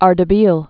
(ärdə-bēl)